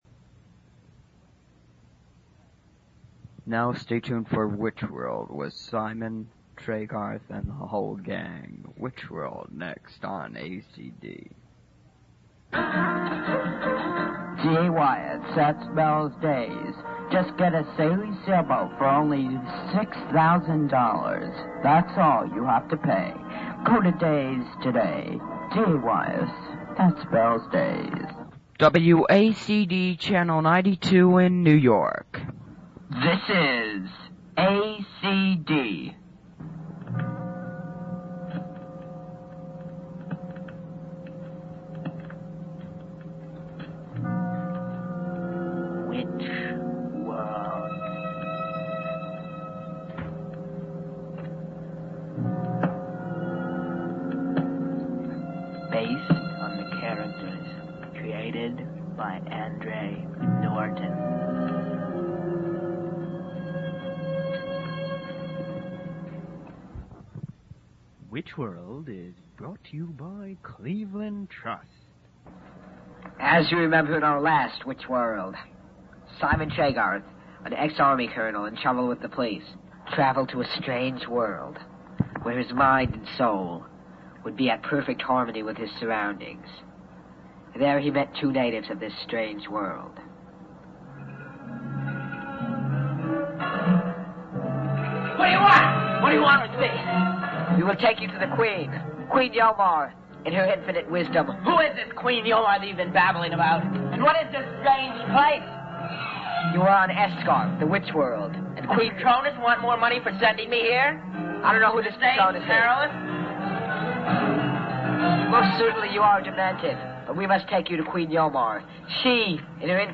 It’s all corny and a bit boring, no?
And, as the episode draws to a close, you can hear the show’s entire premise come crashing down amid heaping helpings of chaos and illogic.